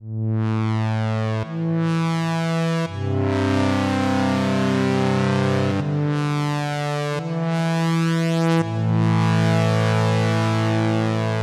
南极洲 " 企鹅在布朗崖
描述：布朗崖的企鹅之声南极半岛，用猎枪式话筒（Schoeps）录制
标签： 企鹅 虚张声势 南极洲 半岛 现场录音 布朗
声道立体声